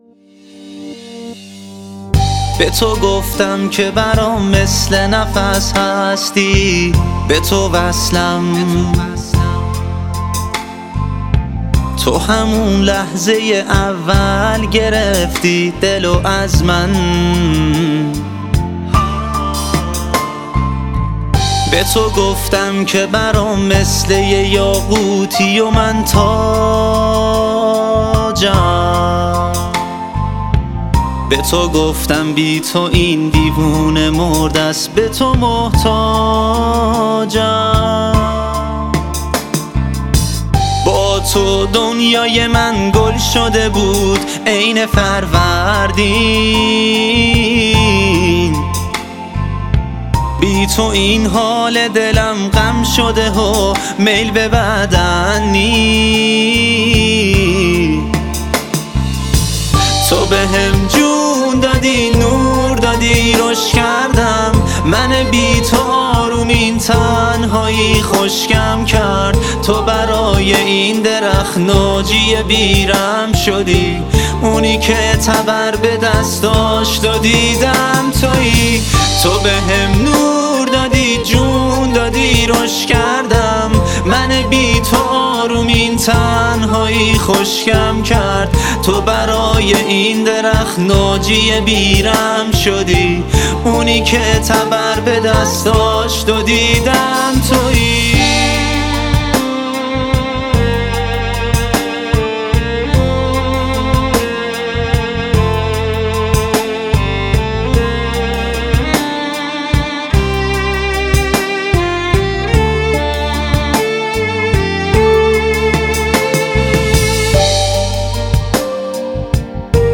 خیلی اهنگ ارام و ملایمی هستش